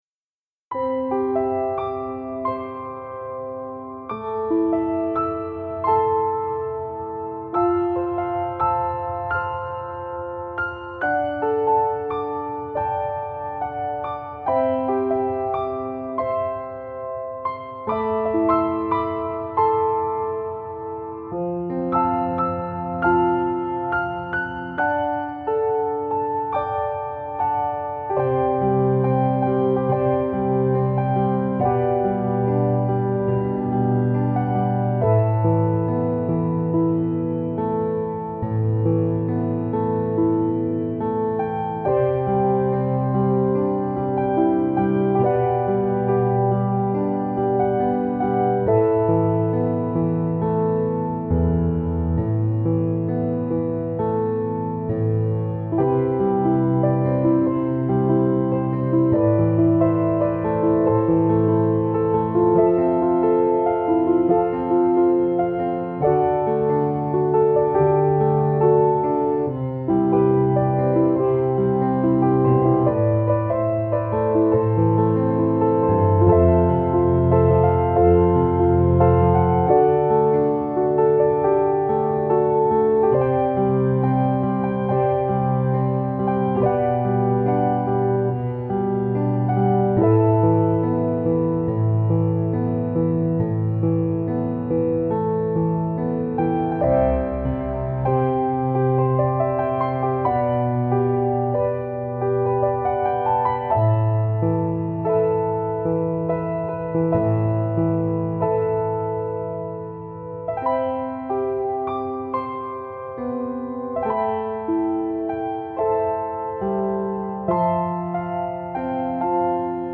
HEALING PIANO MELODIES – PART 3
This medley consists of piano solos for 15 of my songs.
Autumn Recollections – Piano Melody